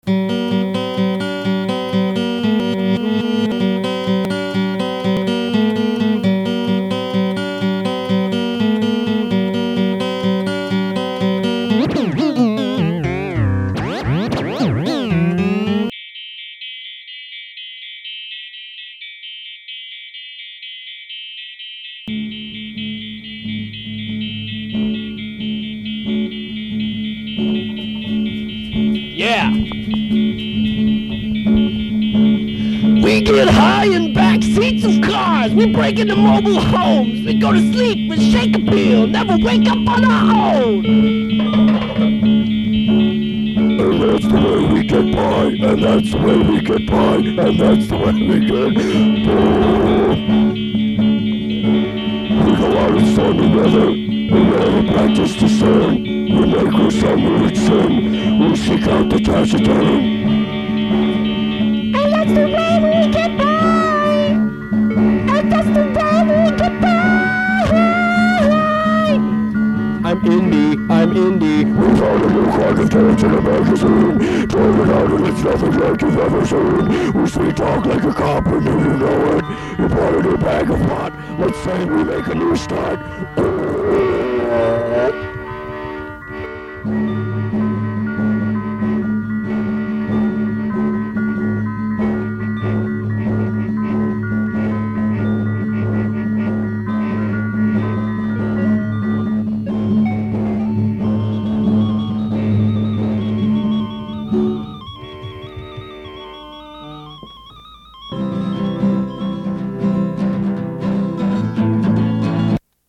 full of awesome covers and fourtrack excellence.
for fans of loud and/or fast-paced songs.